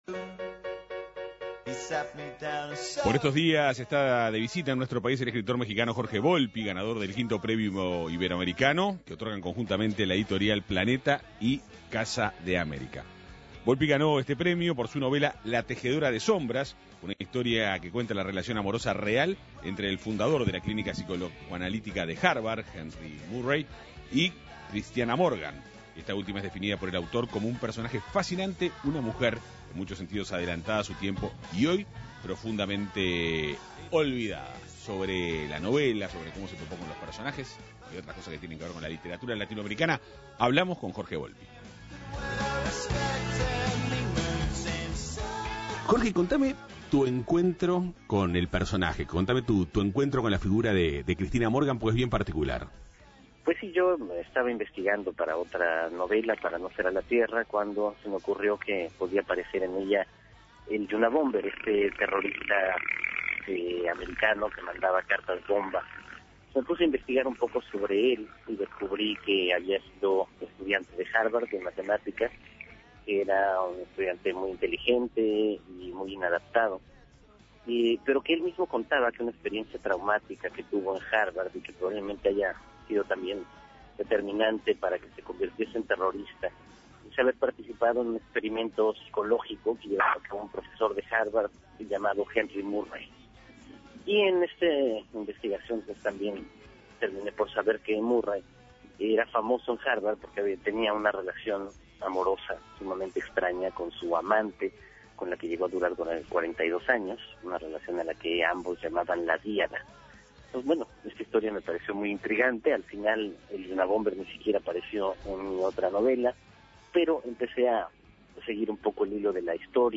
Entrevista a Jorge Volpi
El escritor mexicano Jorge Volpi,dialogó con 810 Vivo,Avances,tendencias y actualidad,sobre la premiación que recibió en el quinto encuentro Iberoamericano,realizado en nuestro país,por su novela "La Tejedora de Sombras".